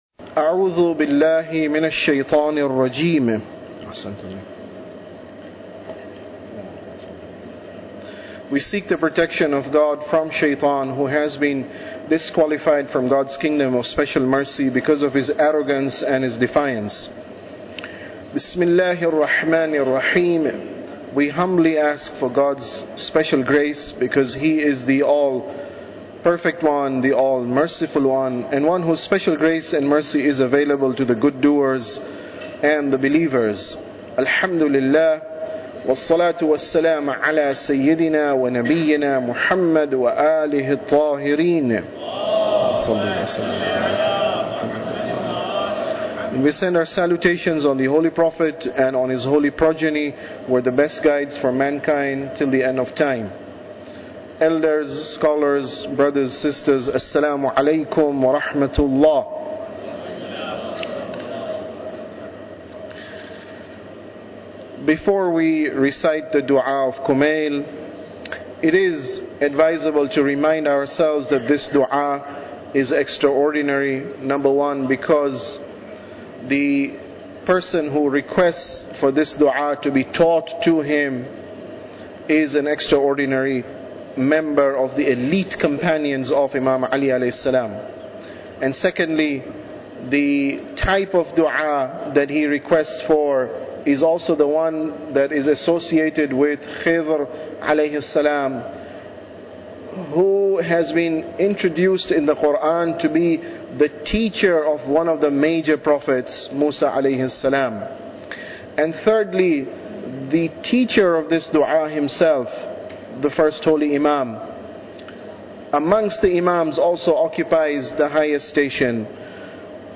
Tafsir Dua Kumail Lecture 18